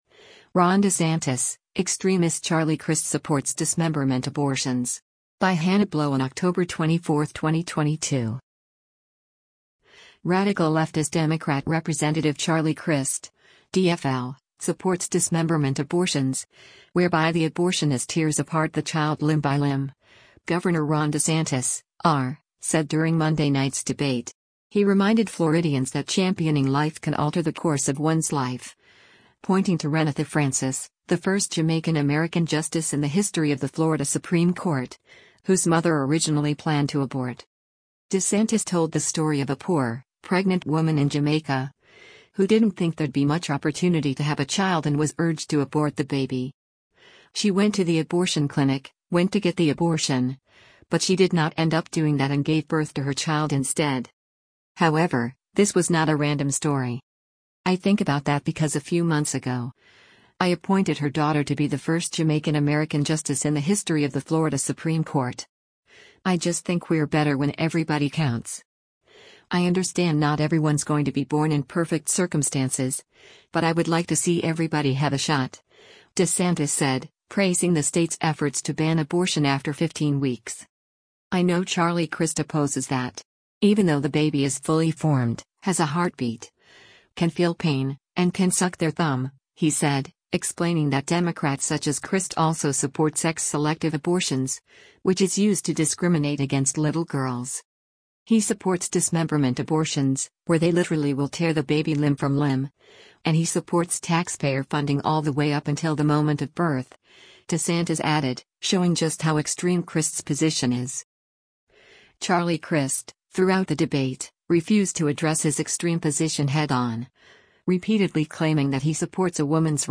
Radical leftist Democrat Rep. Charlie Crist (D-FL) supports “dismemberment” abortions, whereby the abortionist tears apart the child limb by limb, Gov. Ron DeSantis (R) said during Monday night’s debate.